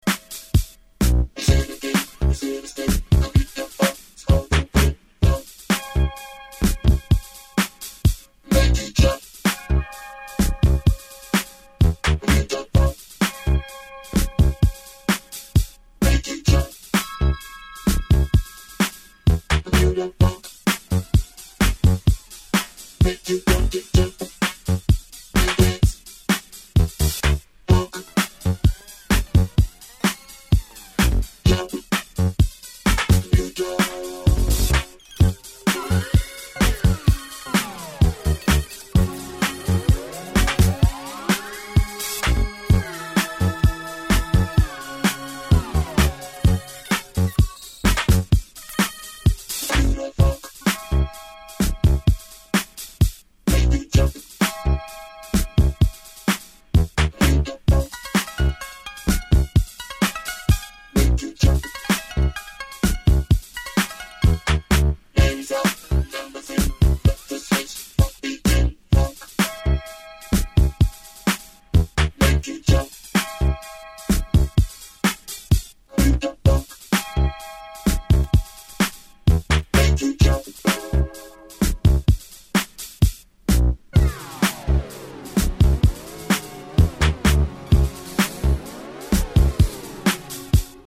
JUNGLE/BREAKBEAT